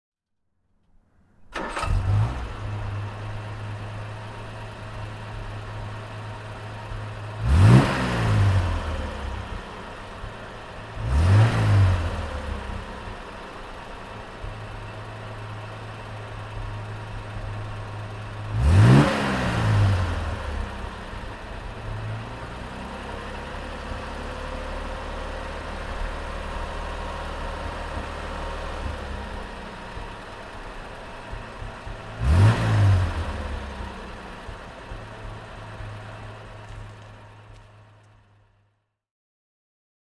Jaguar S 3.8 (1968) - Starten und Leerlauf